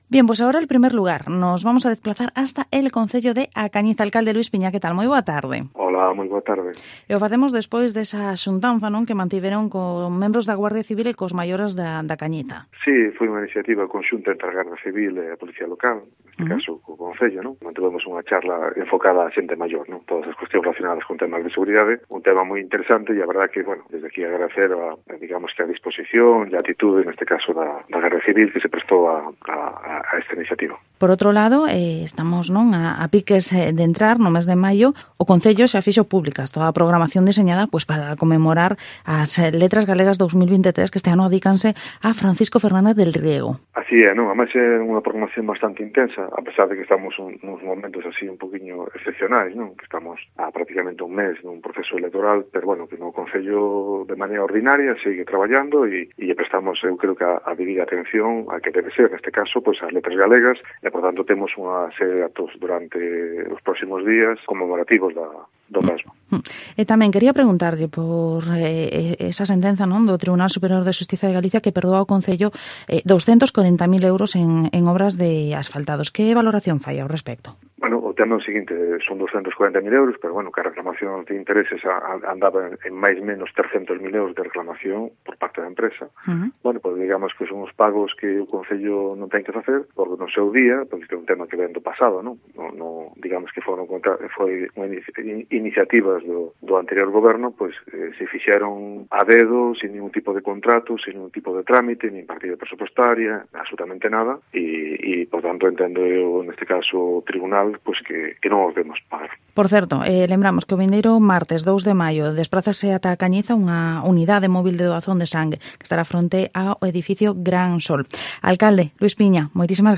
Entrevista al Alcalde de A Cañiza, Luis Piña